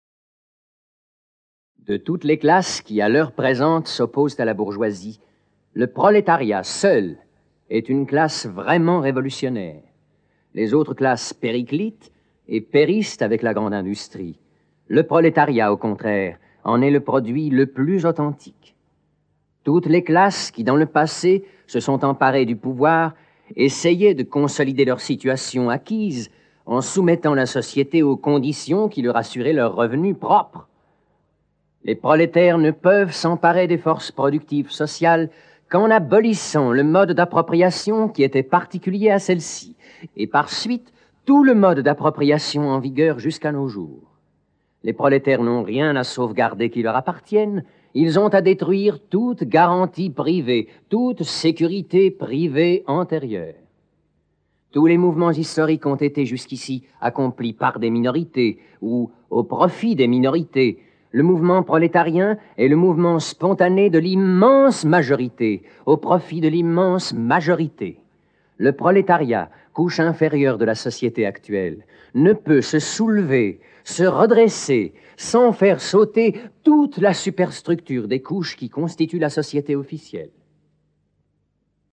Comment vont-ils s'en débrouiller, ensemble cette fois ?Raconté par Gérard Philipe.